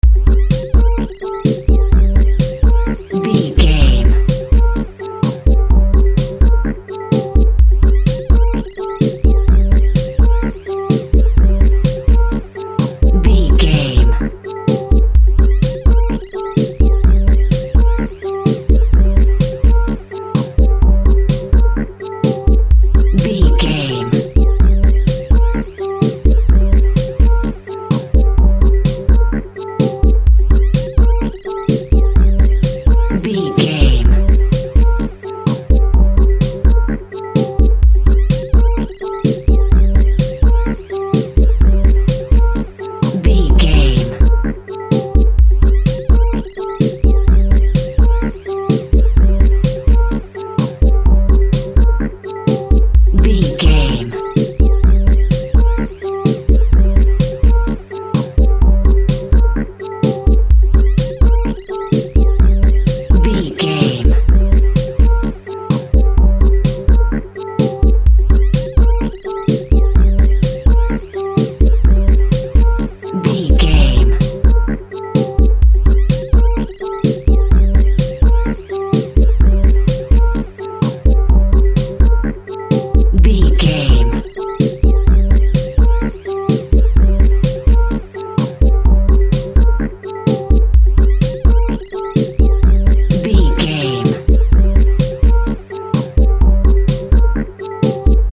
Aeolian/Minor
dark
futuristic
driving
energetic
tension
synthesiser
drums
Drum and bass
break beat
electronic
sub bass
synth lead
synth bass